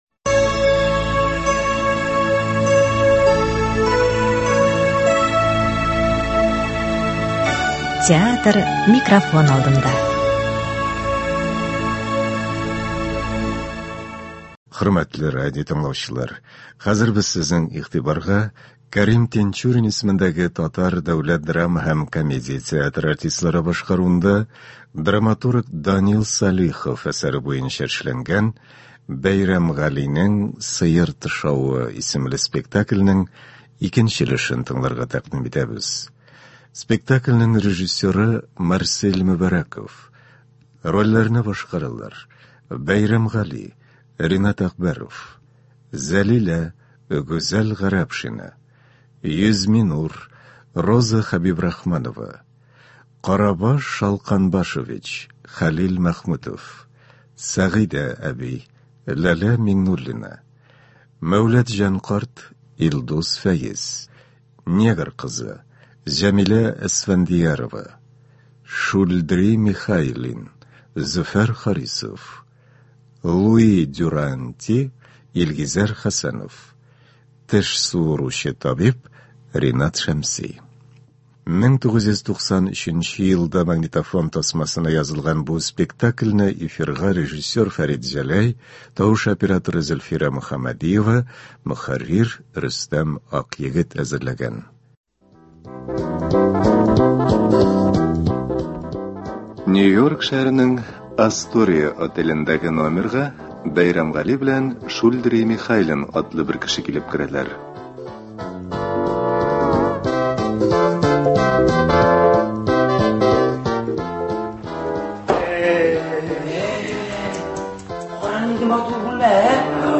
Рольләрне К.Тинчурин исемендәге Татар Дәүләт драма һәм комедия театры артистлары башкара.
Ул магнитофон тасмасына Татарстан радиосы студиясендә 1993 елда язып алынган.